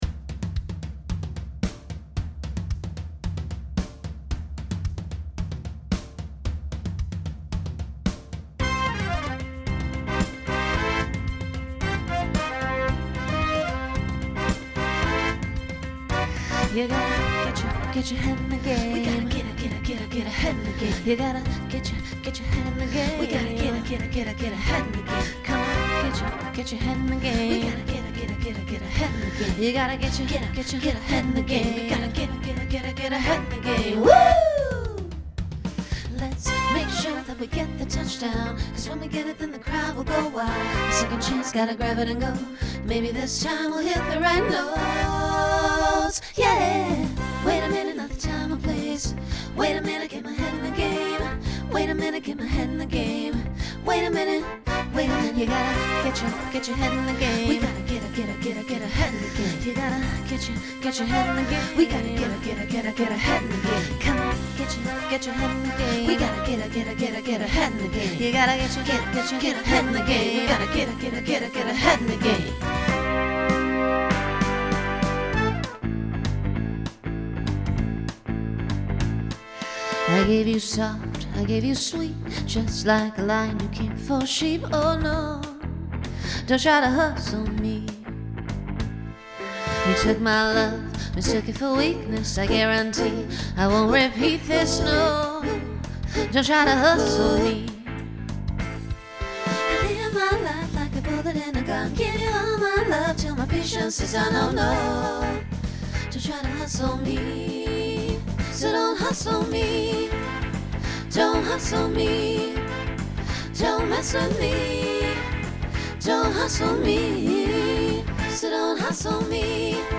2010s Instrumental combo Genre Broadway/Film , Pop/Dance
Voicing SSA